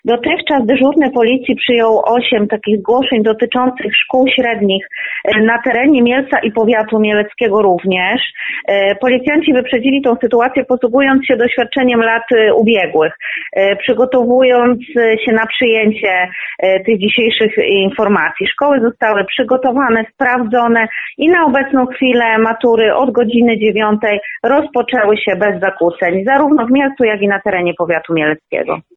Głos w tej sprawie zabiera również mielecka policja.